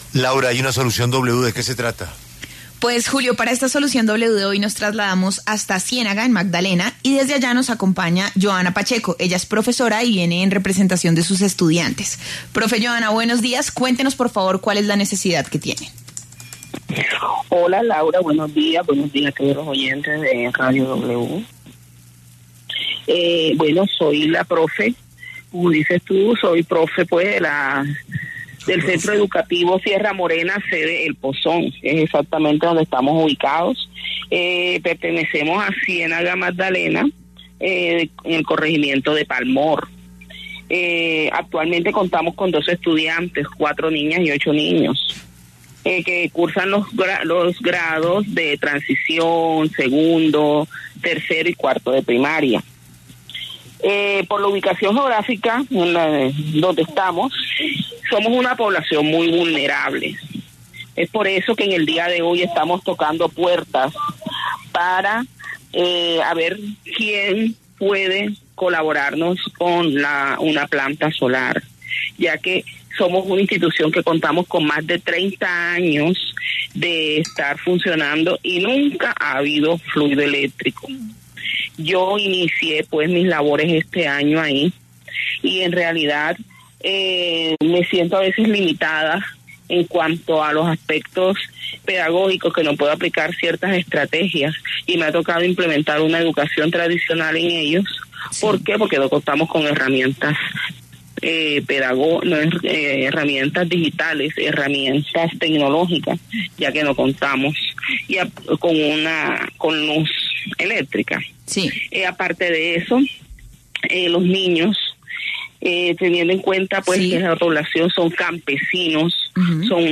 A través de su distribuidor oficial en Colombia, Tienex, anunciaron en vivo que donarán una estación de energía solar portátil Ecoflow con su respectivo panel solar, diseñada para zonas sin acceso a redes eléctricas.